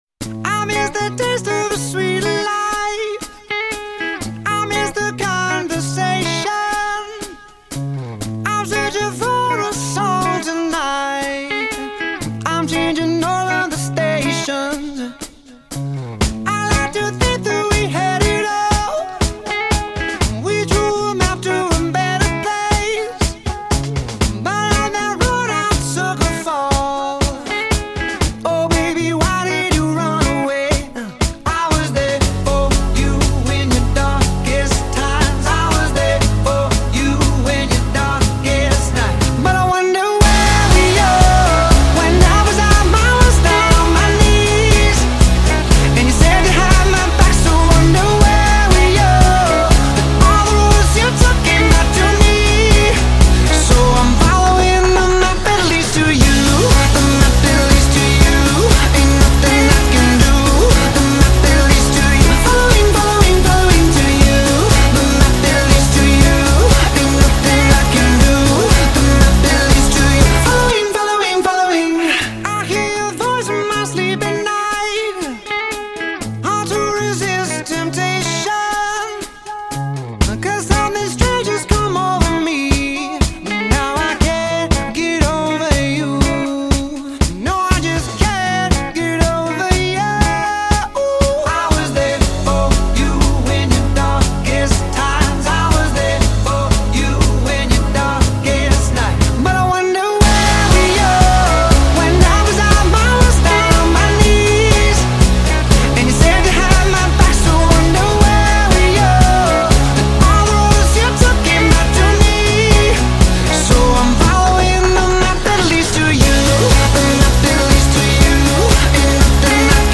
Pop 2010er